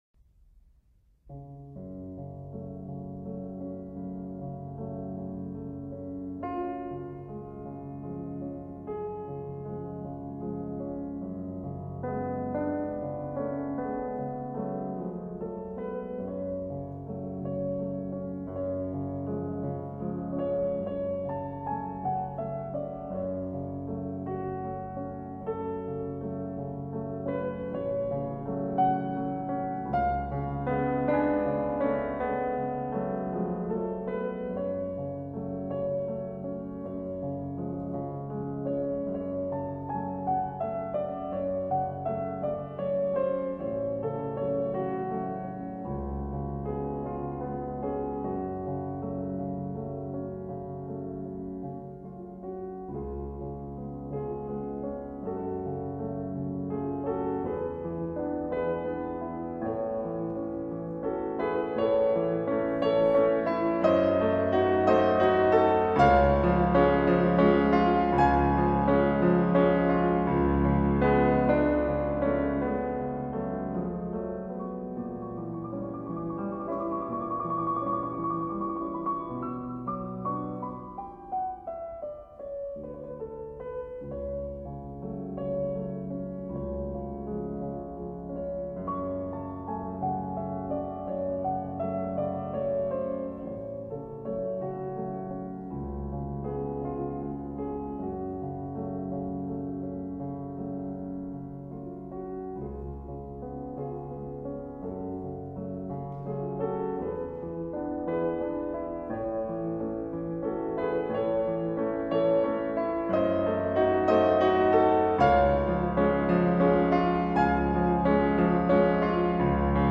和前面4首全部不相同，门德尔松的船歌特别的忧伤，尤其是中段那一连串碎音敲出来的时候，心都可以碎掉~~~
门德尔松的这首船歌选自他著名的组曲“无词歌”，一般被称作威尼斯船歌，有一种黄昏时分在威尼斯的小巷摇曳那些小船的唯美，当然也是伤感的美。
钢琴：席夫